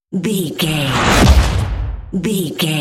Sci fi whoosh to hit fast
Sound Effects
Fast
futuristic
tension
woosh to hit